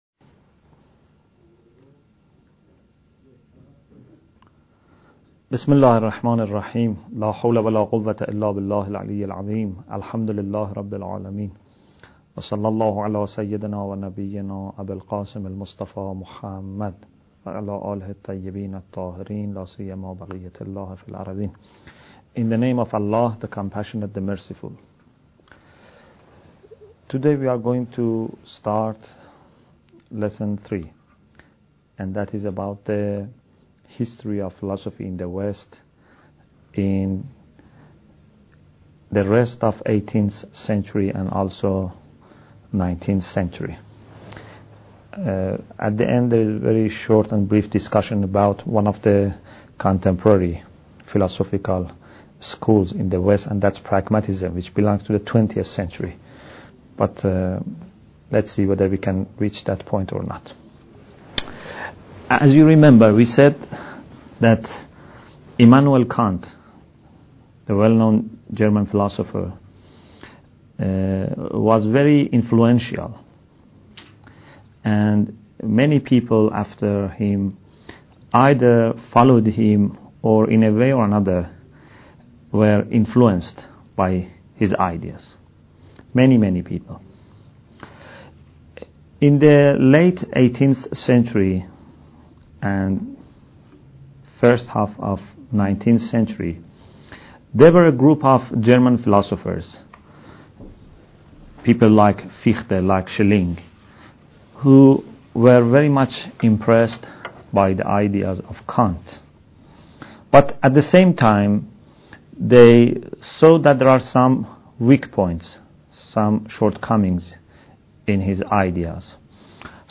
Bidayat Al Hikmah Lecture 6